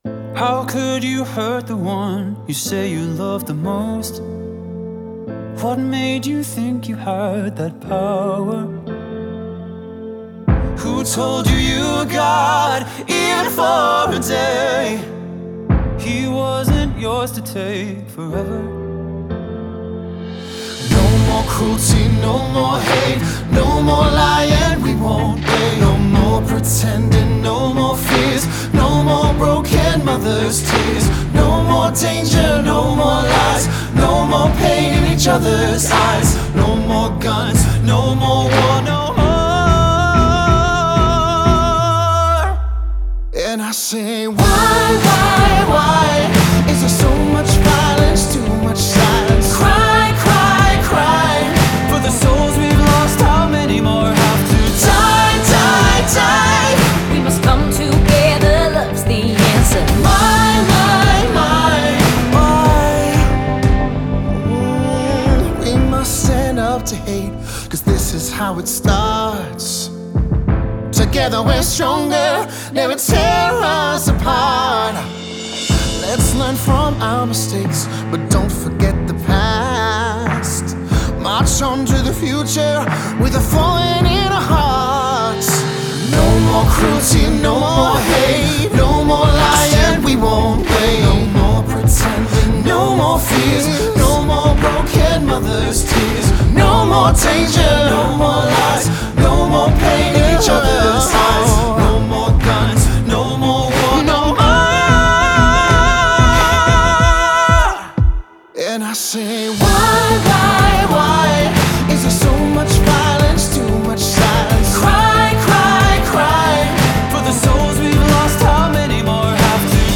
Pop
Soul